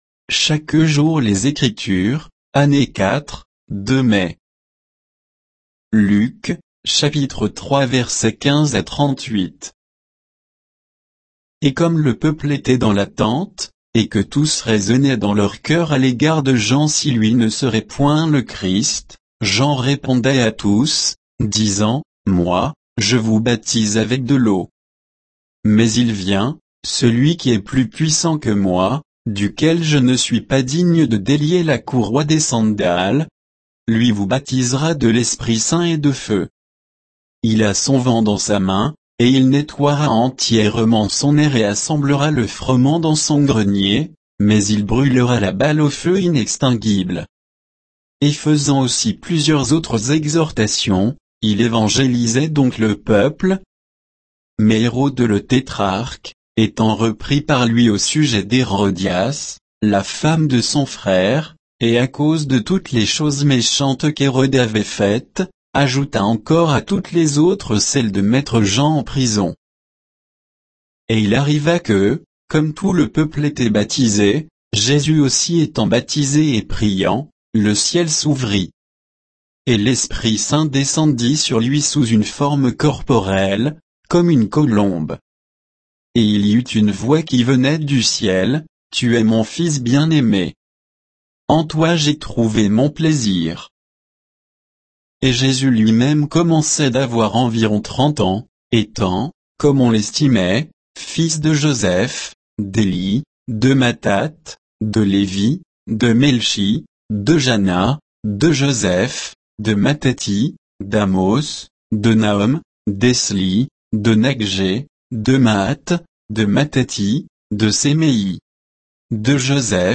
Méditation quoditienne de Chaque jour les Écritures sur Luc 3, 15 à 38